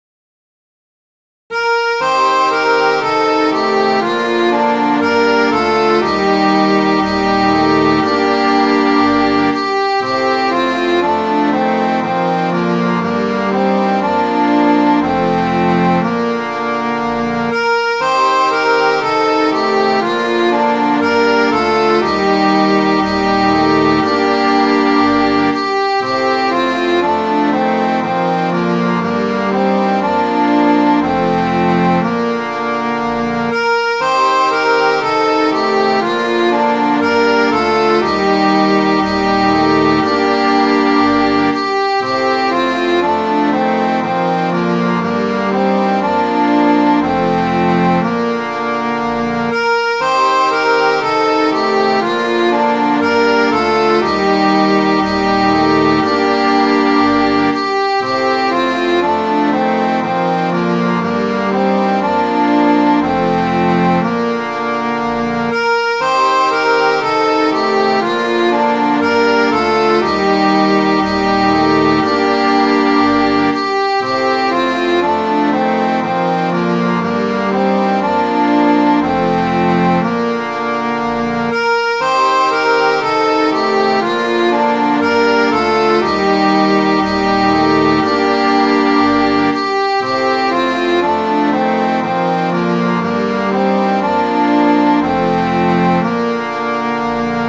Midi File, Lyrics and Information to Won't You Go My Way??